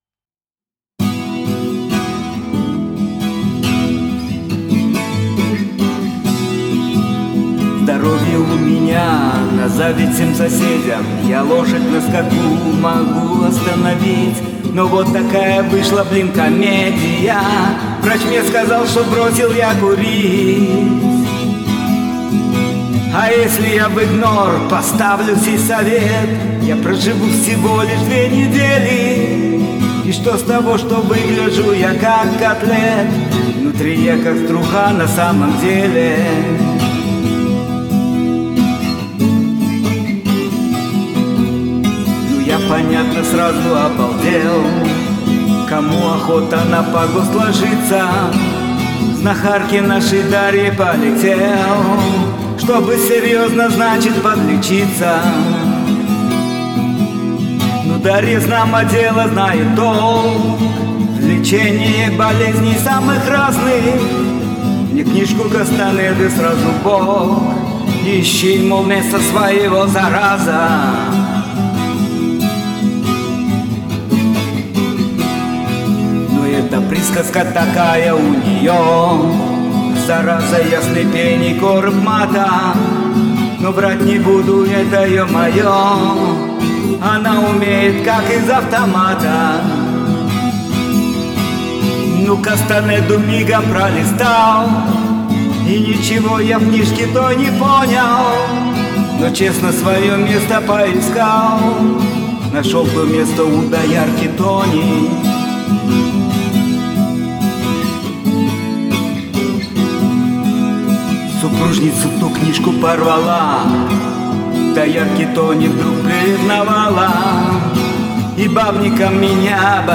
гит